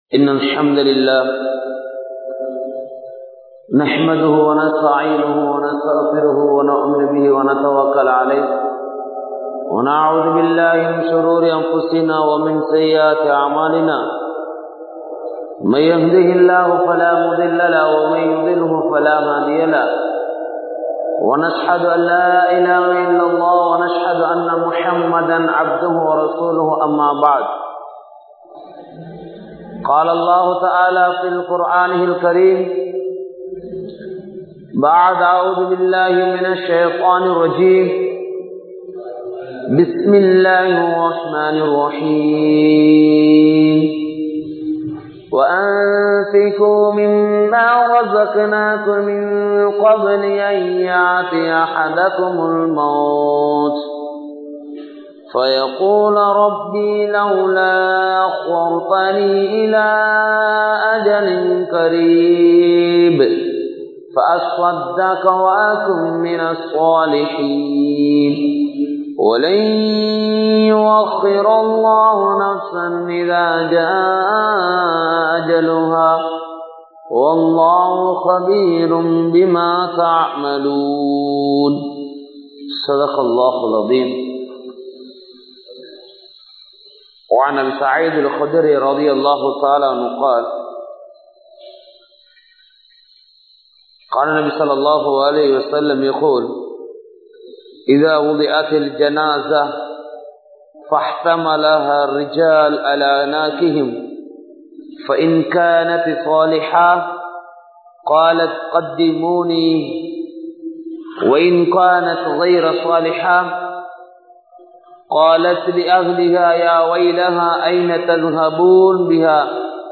Marakka Kodaatha Maranam (மறக்க கூடாத மரணம்) | Audio Bayans | All Ceylon Muslim Youth Community | Addalaichenai